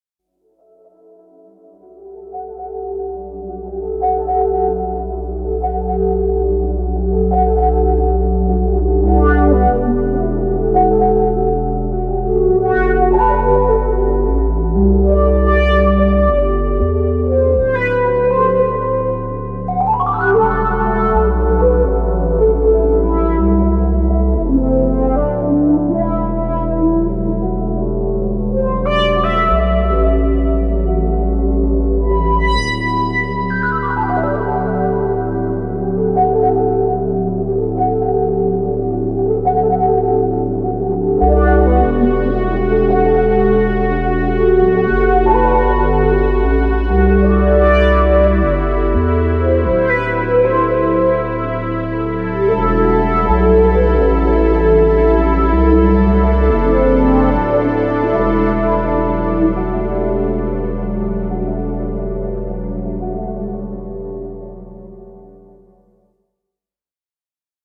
冒頭を飾るモダンなニューエイジ